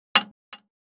Index of /traerlab/AnalogousNonSpeech/assets/stimuli_demos/repeated_impact/small_plastic_longthin_fork
drop03_front.wav